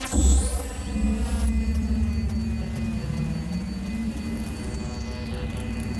Engine 5 Start.wav